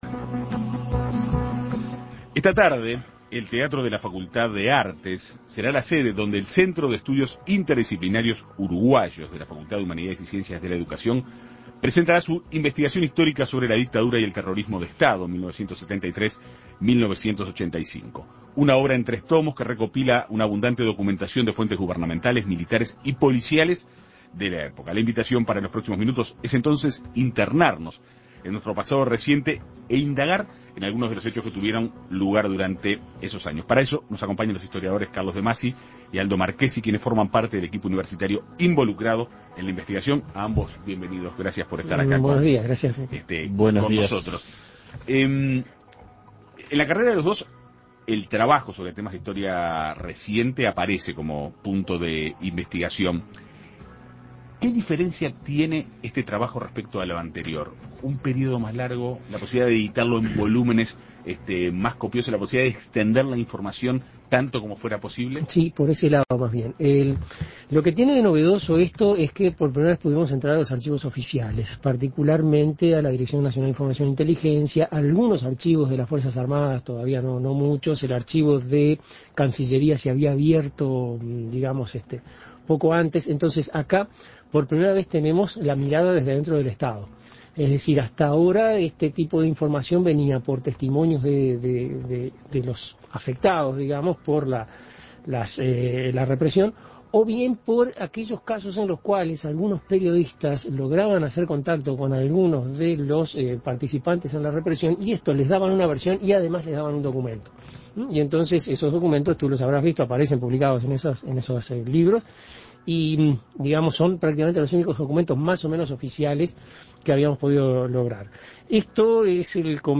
Se trata de una obra de tres tomos que recopila abundante documentación de fuentes gubernamentales, militares y policiales de la época. En Perspectiva Segunda Mañana dialogó con los historiadores